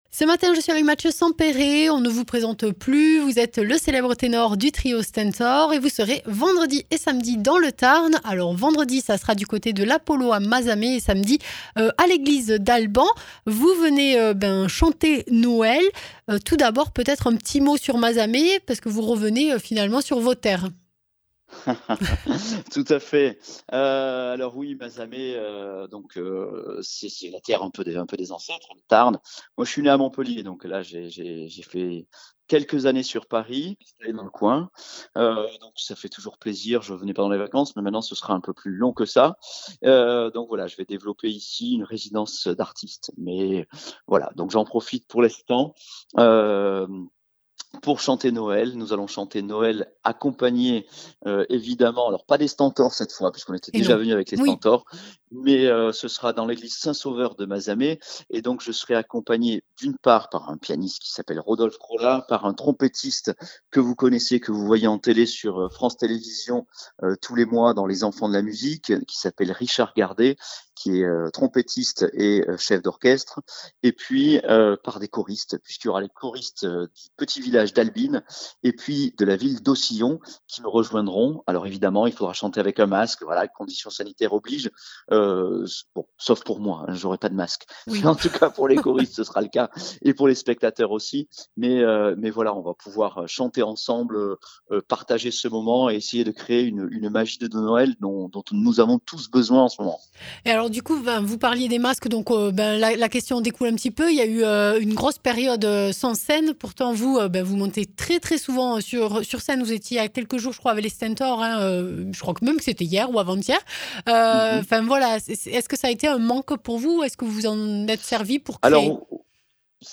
Interviews
Invité(s) : Mathieu Sempéré, ténor